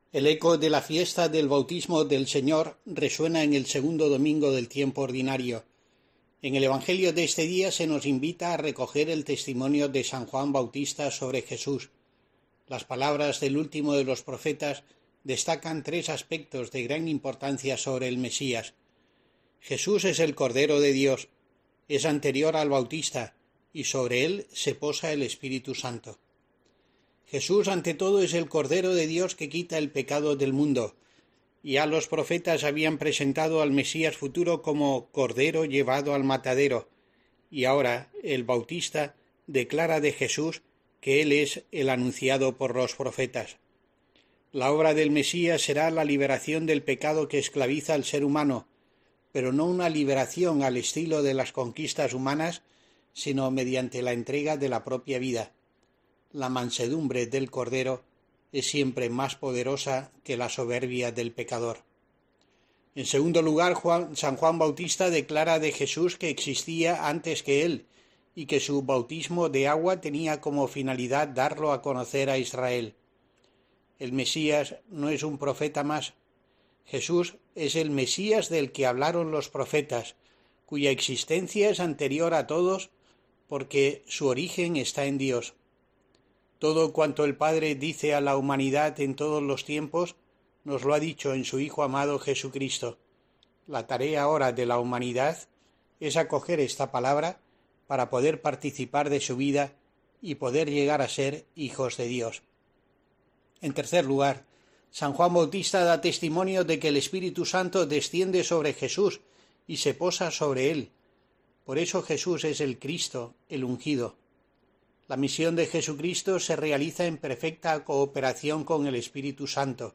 Escucha aquí la reflexión semanal del obispo de Asidonia-Jerez para COPE de cara al segundo domingo del Tiempo Ordinario